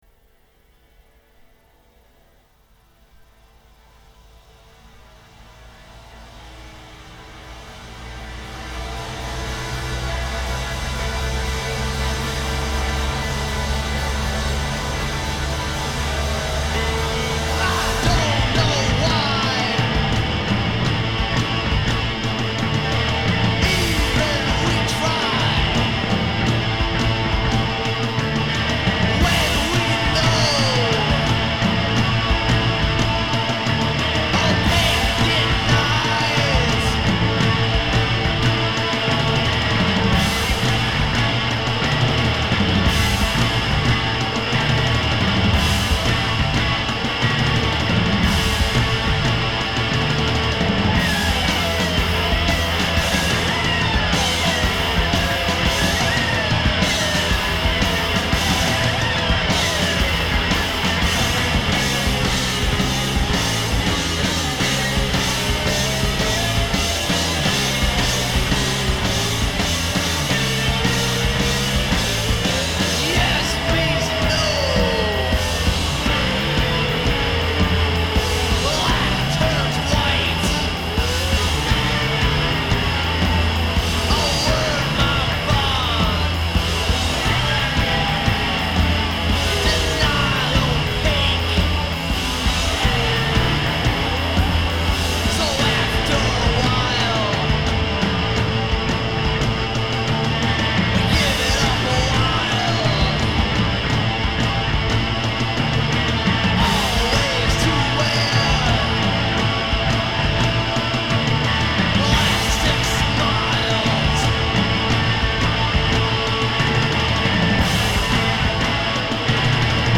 High volume nasty noise at full industrial strength levels.